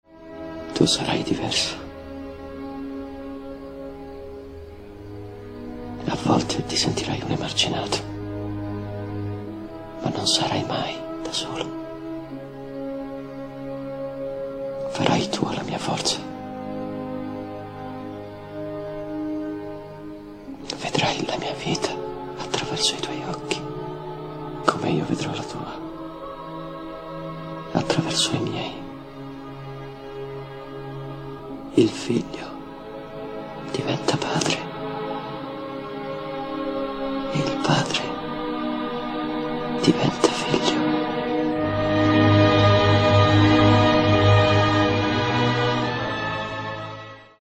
estratto dal Film
TEATRO, DOPPIAGGIO